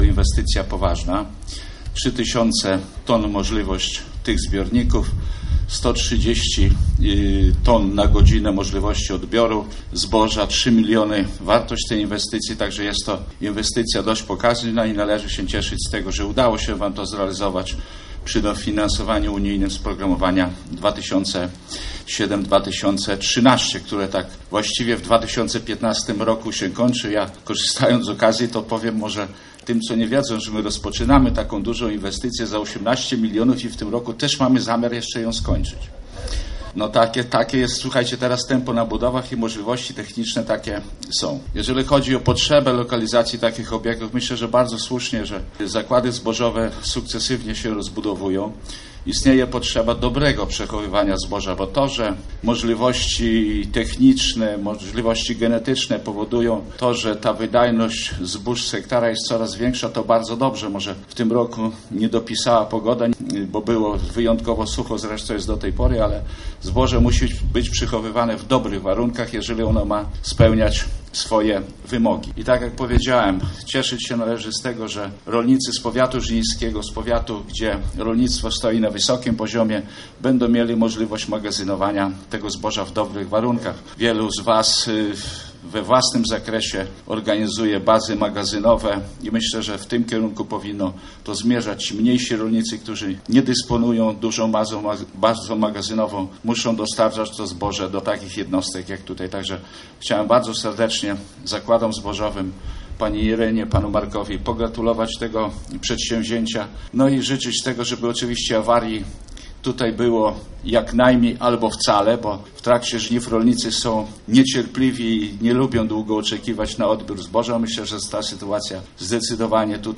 O znaczeniu tej inwestycji dla rolnictwa w powiecie żnińskim mówił również Starosta Żniński Zbigniew Jaszczuk, życząc właścicielom jak najmniej awarii nowego sprzętu.